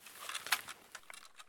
hide_rifle.ogg